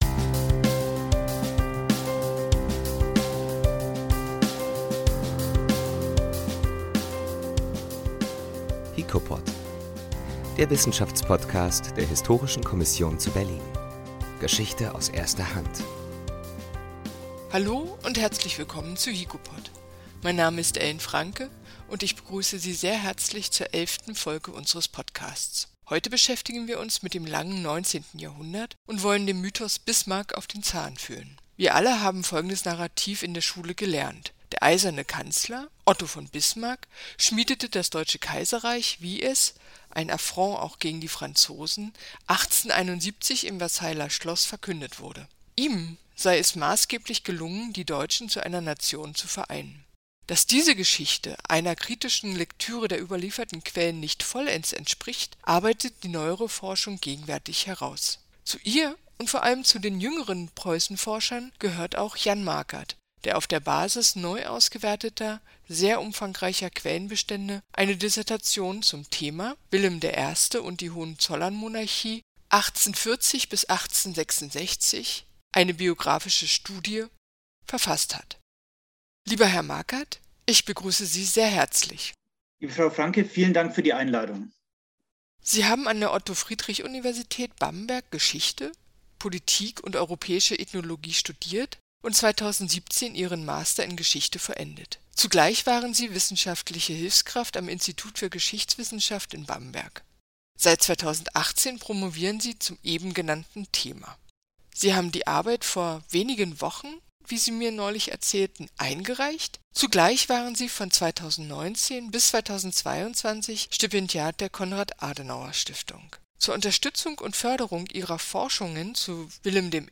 11 I HiKoPod I Kein Bismarckreich, sondern ein Kaiserreich – Ein Interview